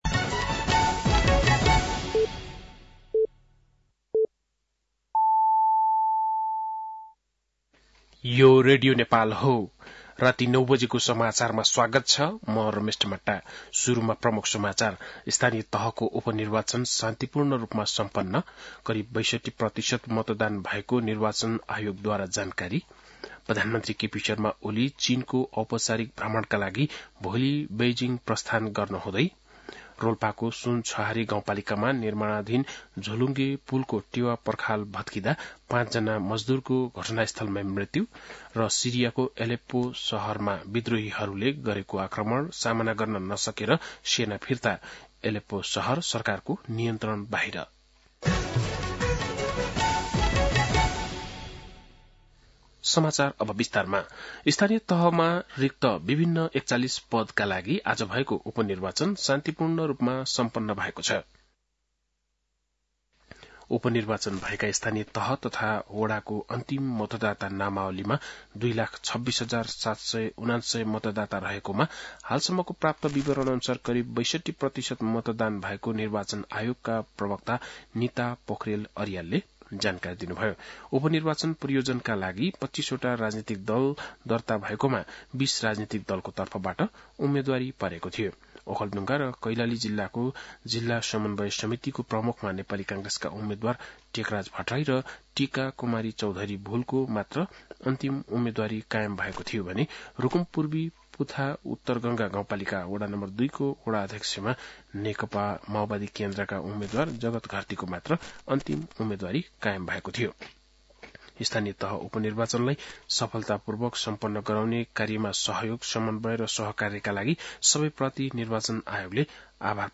बेलुकी ९ बजेको नेपाली समाचार : १७ मंसिर , २०८१
9-PM-Nepali-News-8-16.mp3